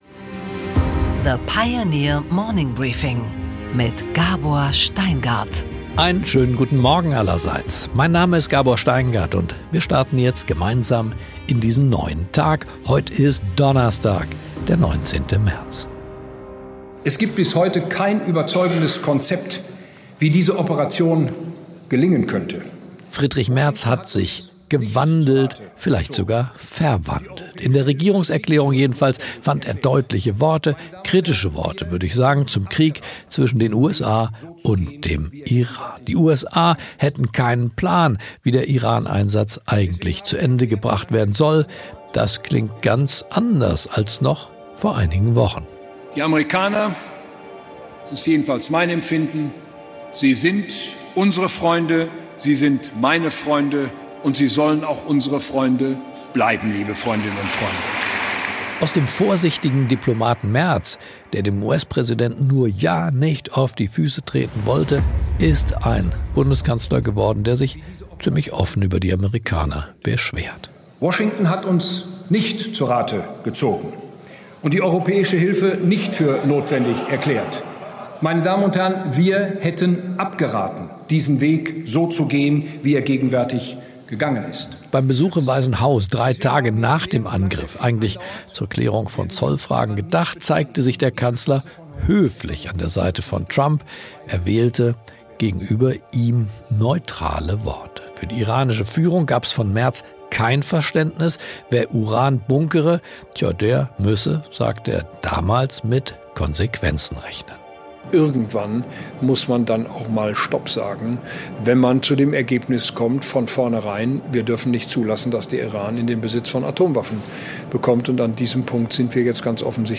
Gabor Steingart präsentiert das Morning Briefing.
Im Gespräch: Christian Klein, CEO von SAP, spricht auf der Pioneer Two mit Gabor Steingart über seinen Weg vom Werkstudenten zum CEO, den Druck beim Umbau des Unternehmens und darüber, was Künstliche Intelligenz kann und auch nicht kann.